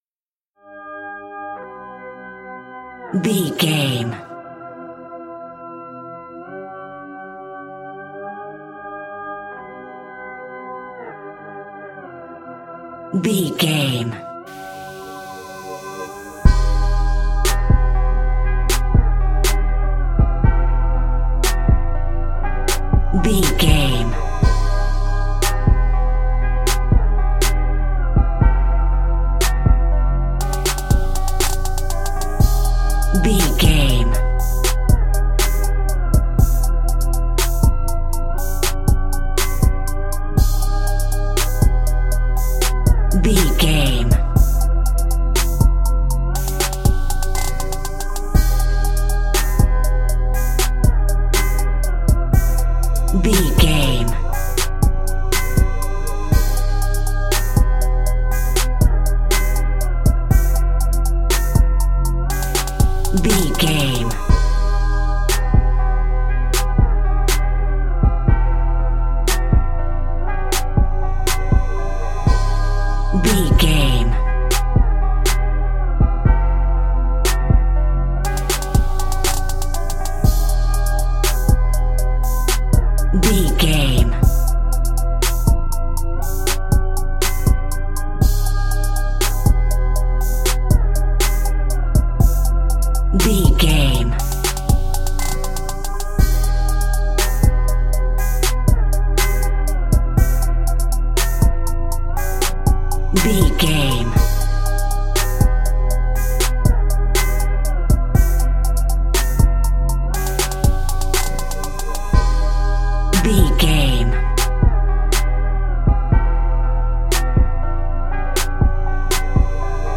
Aeolian/Minor
chilled
laid back
groove
hip hop drums
hip hop synths
piano
hip hop pads